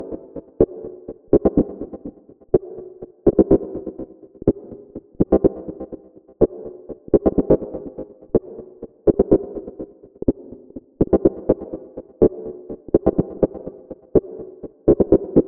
• house mid bass pluck keep it sequence 124 - Cm.wav
house_mid_bass_pluck_keep_it_sequence_124_-_Cm_ST1.wav